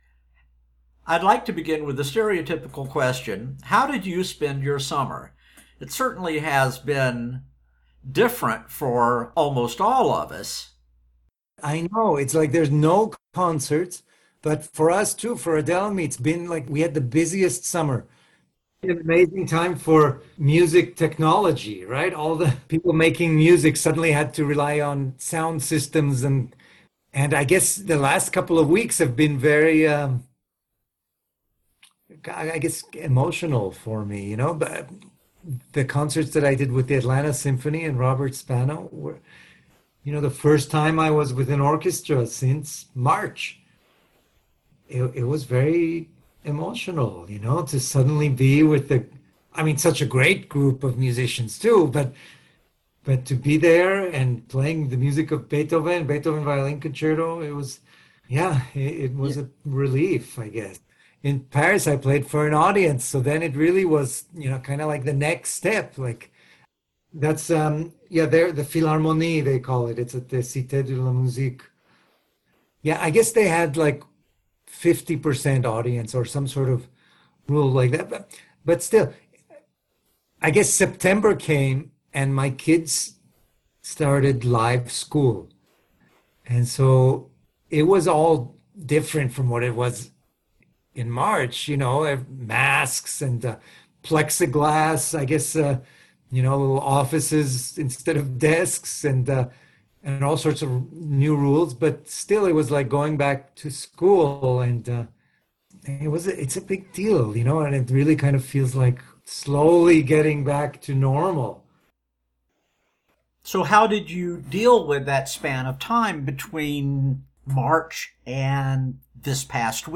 We were able to connect on October 2 while Shaham was isolated in a hotel in Hanover, Germany in advance of performing concerts there with the NDR Orchestra the next week.